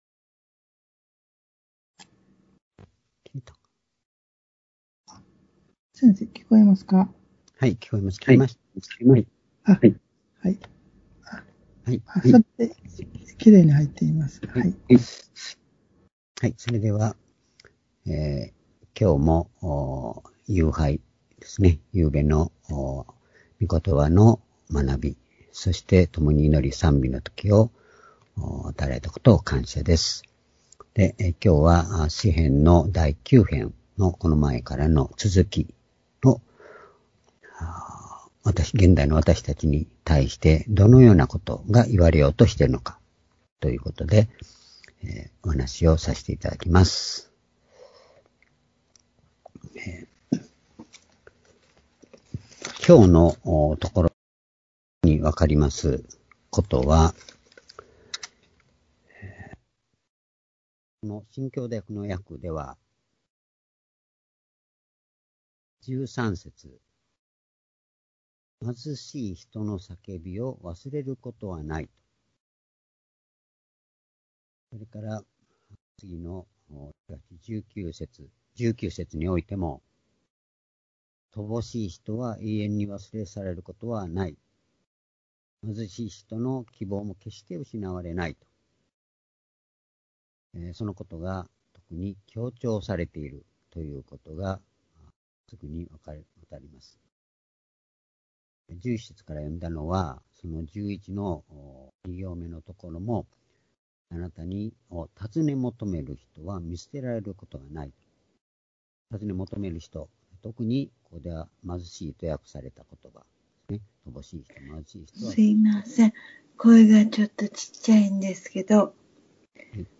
（主日・夕拝）礼拝日時 2020年10日20日夕拝 聖書講話箇所 「苦しみ人の叫びを覚えてくださる主」 詩編9篇11節〜21節 ※視聴できない場合は をクリックしてください。